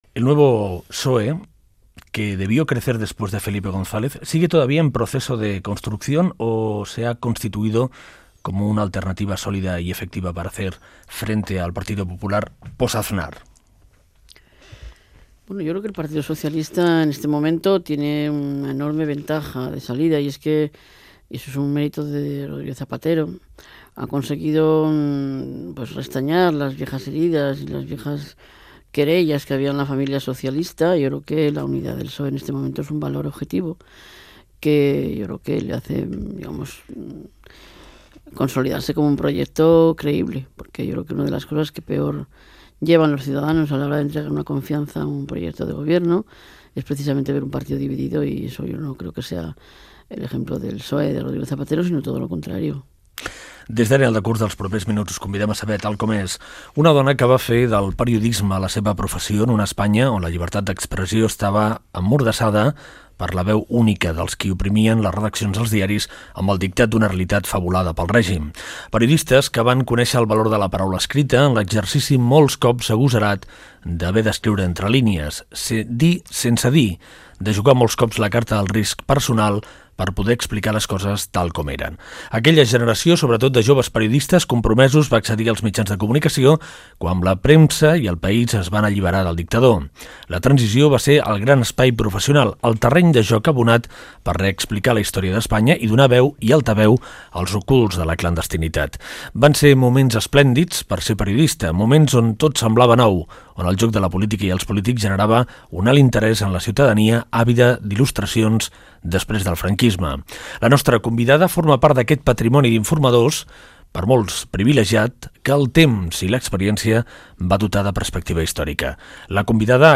Fragment d'una entrevista a la periodista María Antonia Iglesias, autora del llibre "La memoria recuperada", sobre els 14 anys de governs socialistes, fins aleshores.